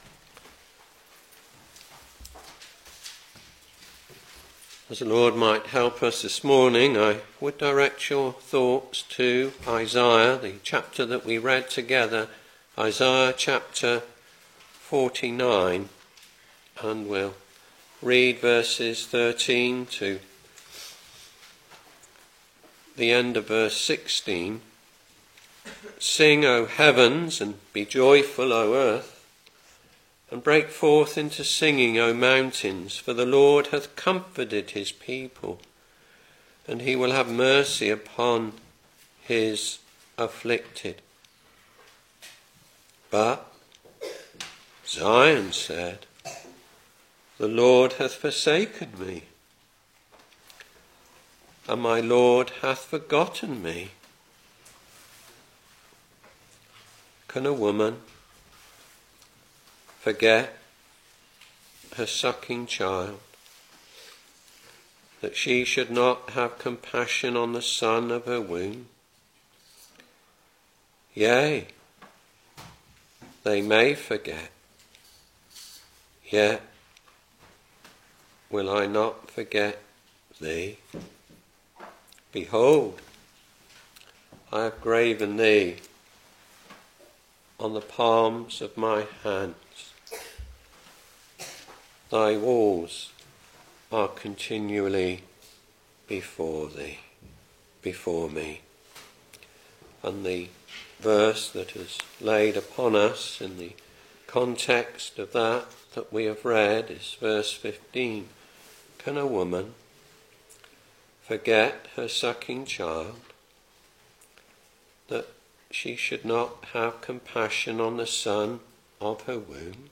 Back to Sermons Isaiah Ch.49 v.13 to v.16